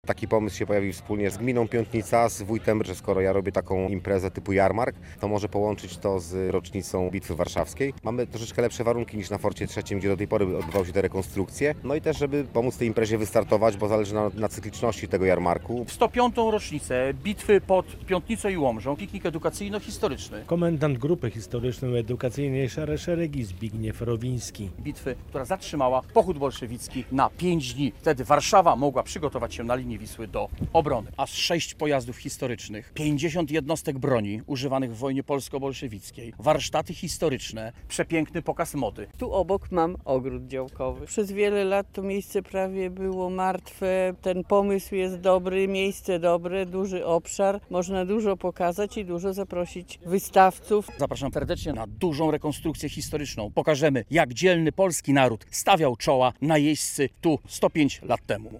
Impreza w Piątnicy łącząca jarmark staroci i piknik historyczno-edukacyjny - relacja